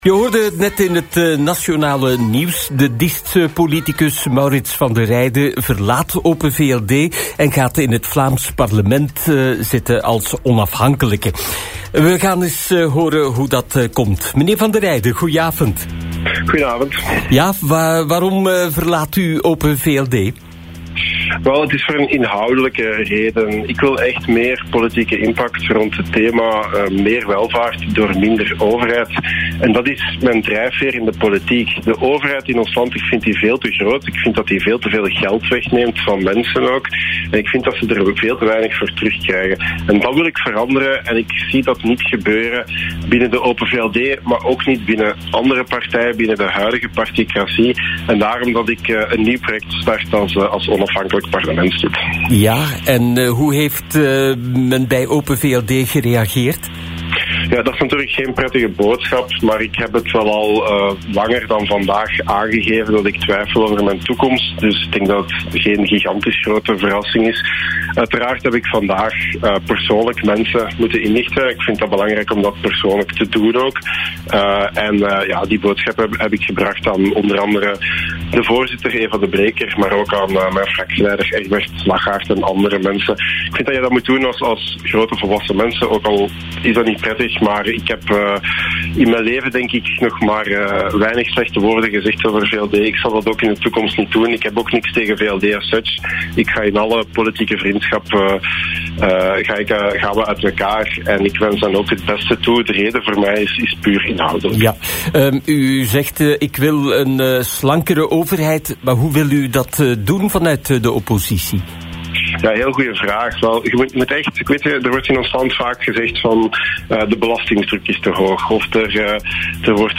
Beluister hier het interview met Maurits Vande Reyde.